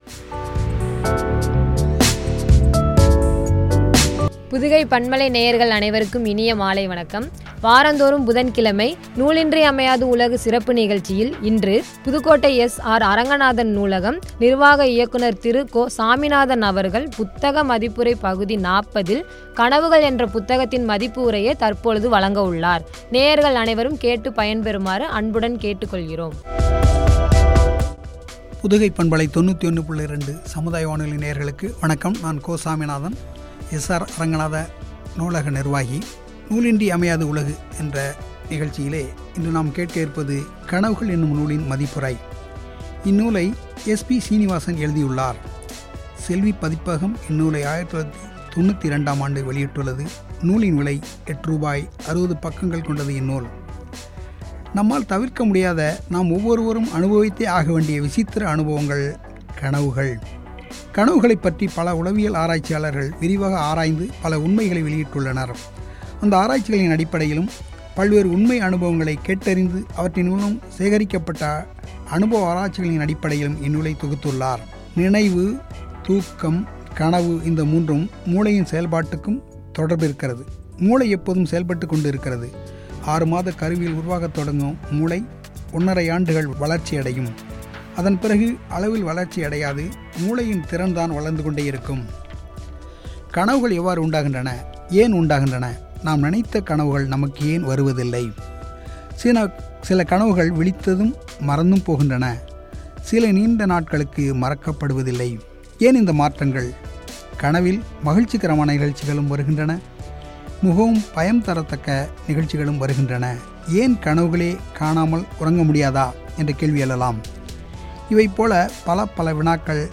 “கனவுகள்” புத்தக மதிப்புரை (பகுதி – 40), குறித்து வழங்கிய உரையாடல்.